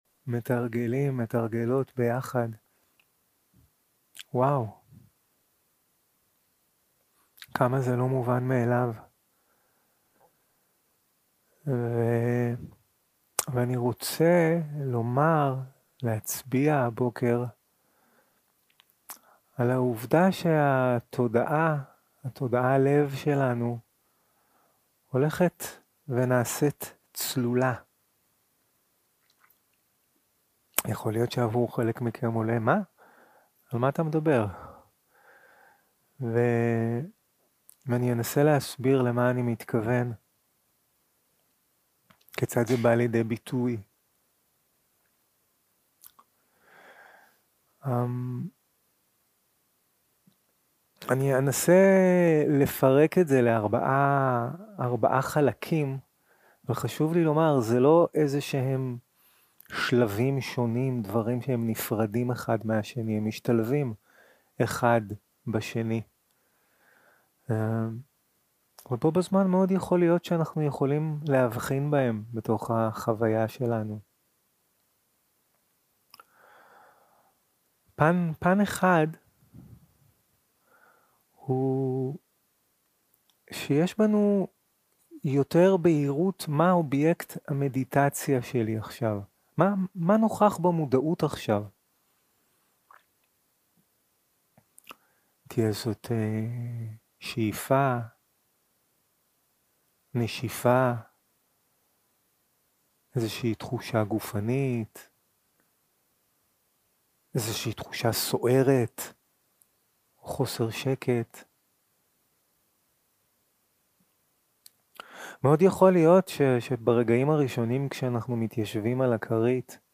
יום 3 - הקלטה 5 - בוקר - הנחיות למדיטציה
שיחת הנחיות למדיטציה